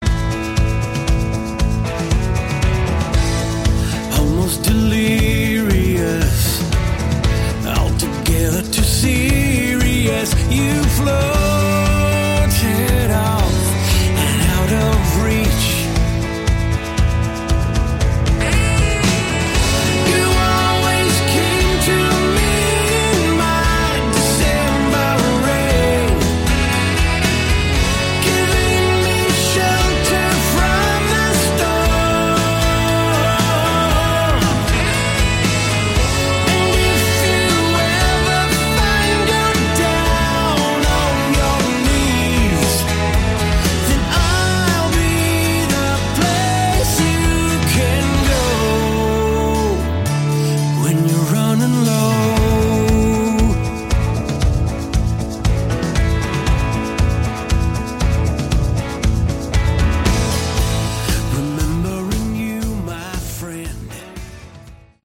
Category: Light AOR
saxophone
keyboards, vocals
guitar, vocals
drums
bass